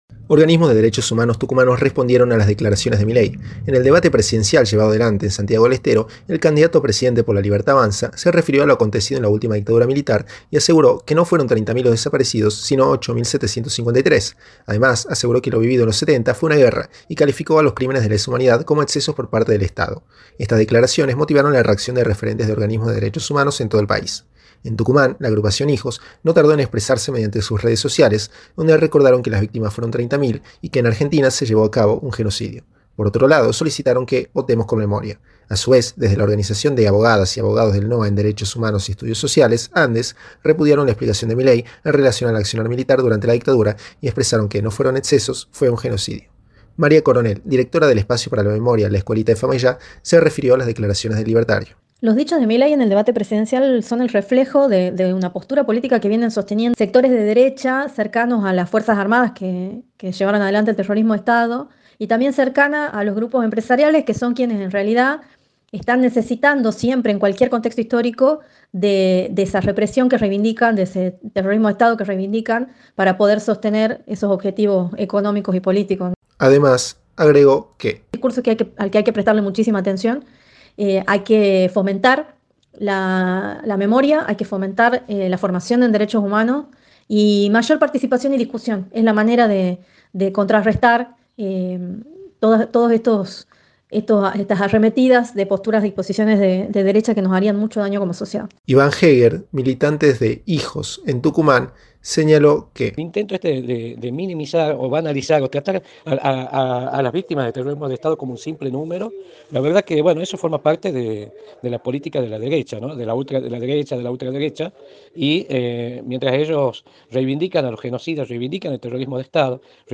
reporte